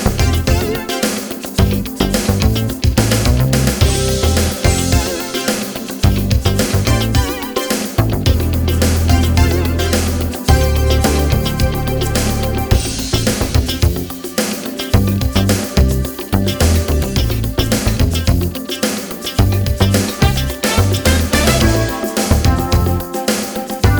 no Backing Vocals R'n'B / Hip Hop 4:12 Buy £1.50